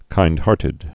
(kīndhärtĭd)